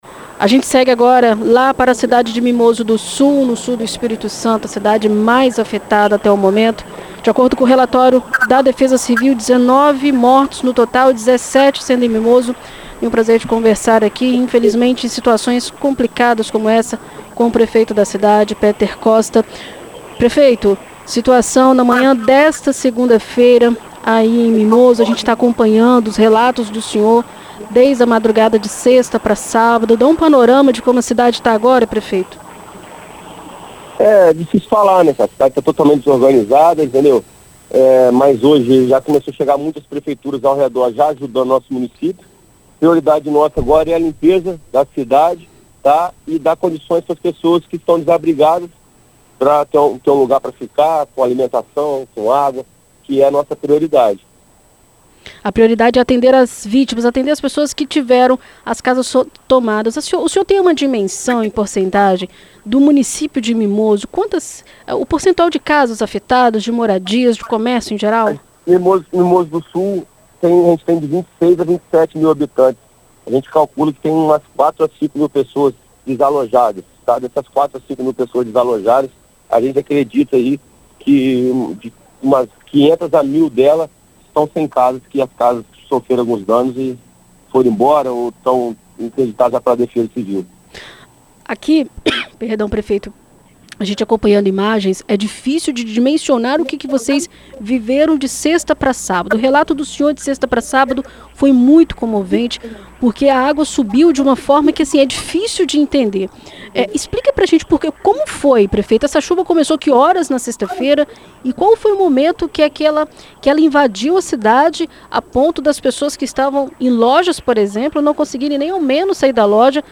O prefeito de Mimoso do Sul, Peter Costa, conversa com a BandNews FM ES
Em entrevista à BandNews FM Espírito Santo nesta segunda-feira (25), o prefeito de Mimoso do Sul, Peter Costa, fala sobre os trabalhos que têm sido realizados na cidade.